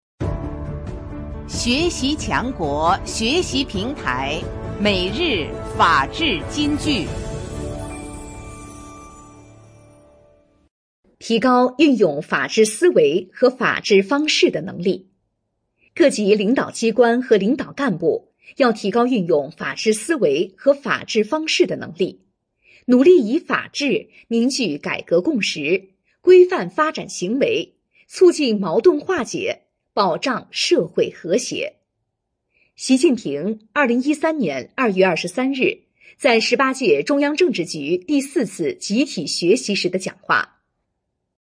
每日法治金句（朗读版）|提高运用法治思维和法治方式的能力 _ 学习宣传 _ 福建省民政厅